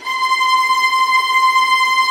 VIOLINT DN-R.wav